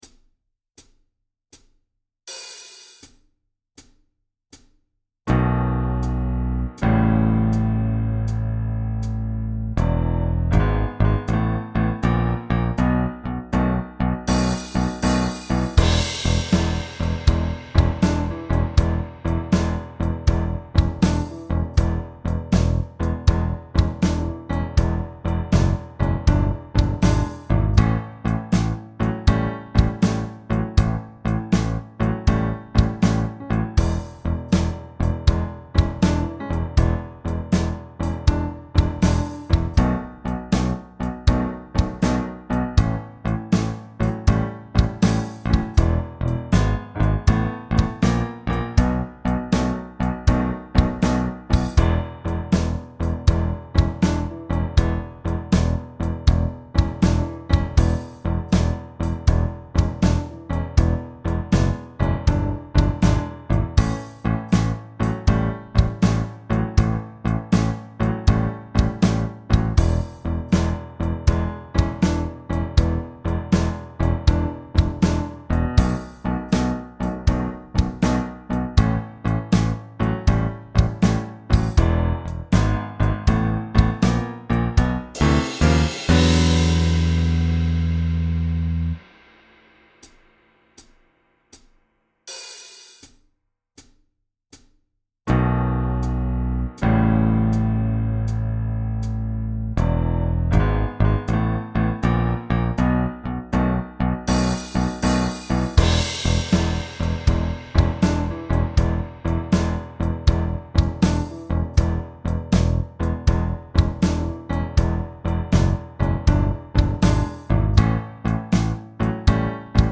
Minus Guitar Part